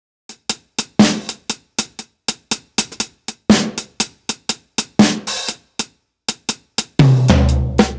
Unison Jazz - 3 - 120bpm - Tops.wav